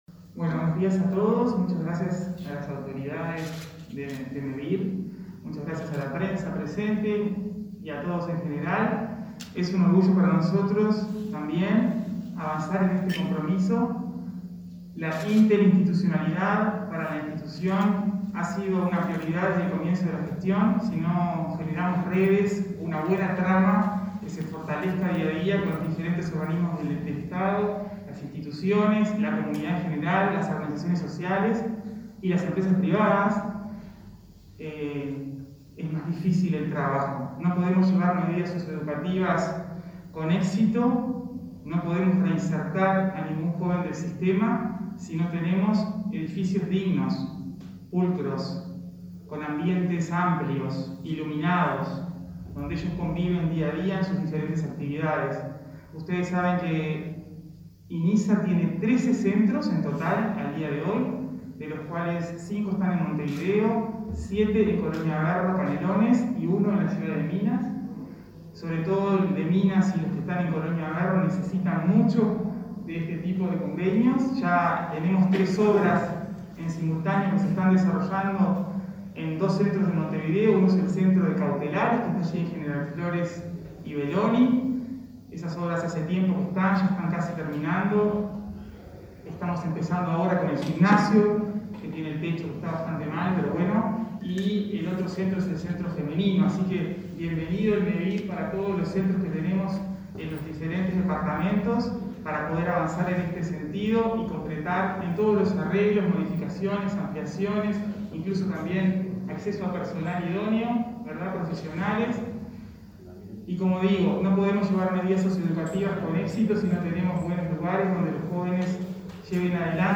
Palabras de la presidenta del Inisa, Rosanna de Olivera, y del titular de Mevir, Juan Pablo Delgado